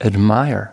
/kəˈlek.ʃən/